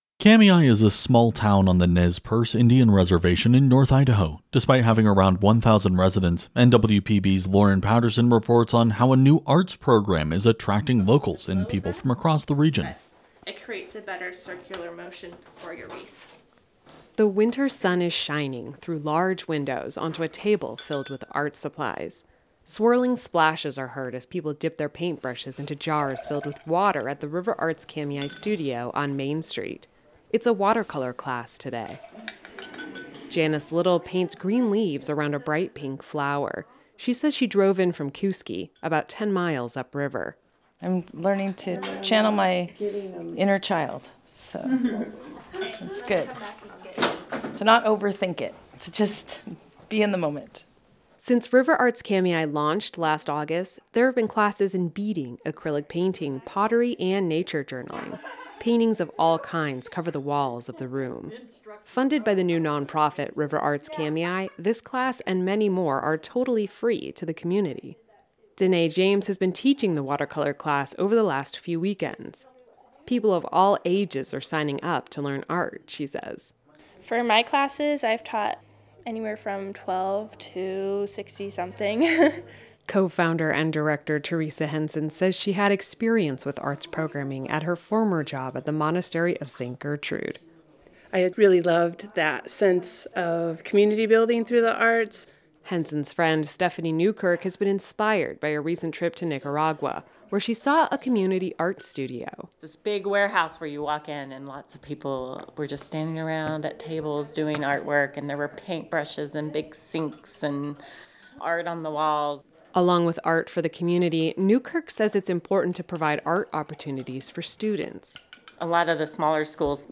Swirling splashes are heard as people dip their paint brushes into jars filled with water. There’s a watercolor class underway.